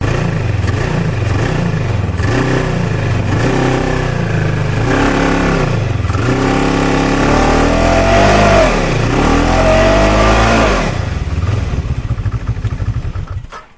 На этой странице собраны звуки снегохода: рев мотора, скрип снега под гусеницами, свист ветра на скорости.
Звук ревущего мотора снеговода на газу